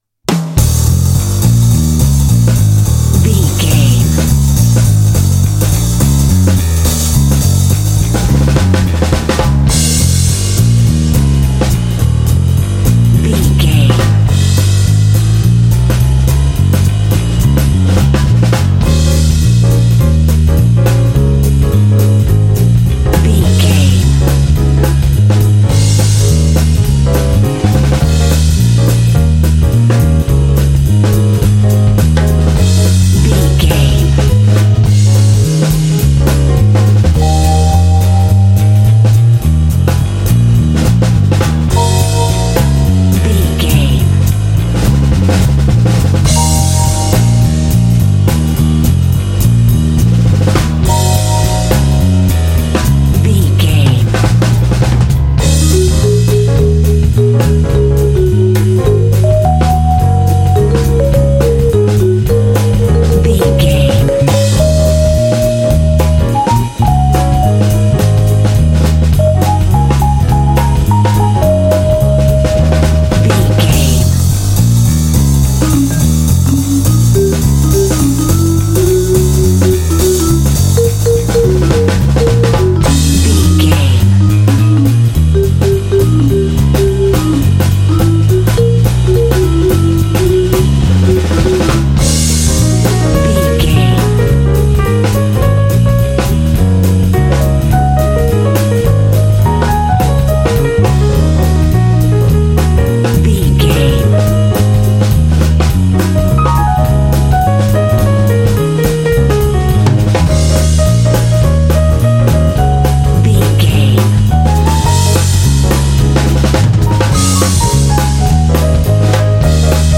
Uplifting
Aeolian/Minor
E♭
epic
driving
energetic
groovy
lively
bass guitar
piano
jazz
big band